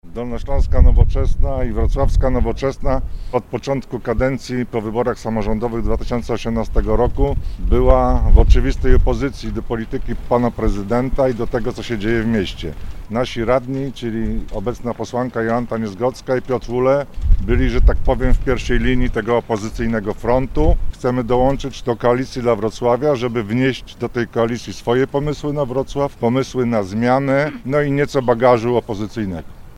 – Przystępujemy do Koalicji dla Wrocławia, żeby wnieść swoje pomysły na Wrocław, pomysły na zmianę – mówi Tadeusz Garbarek, szef dolnośląskich struktur partii i wrocławski radny.